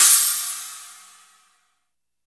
16 CRASH.wav